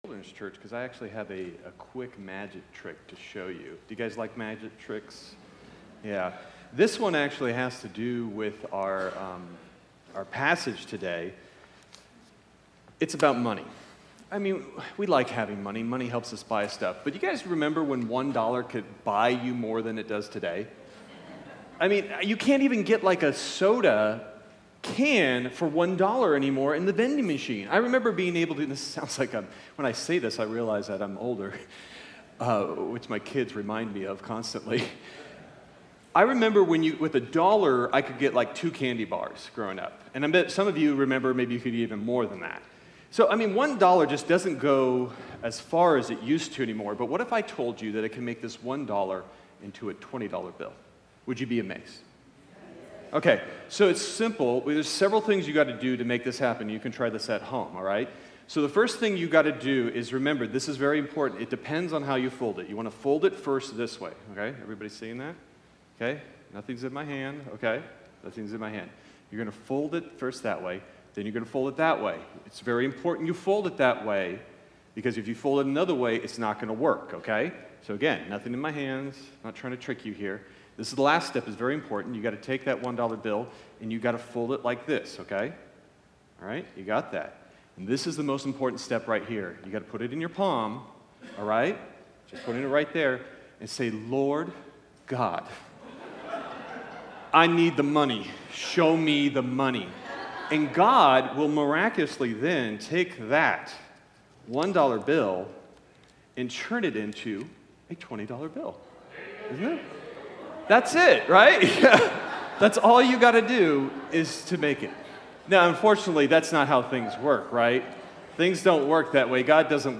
Sermon Detail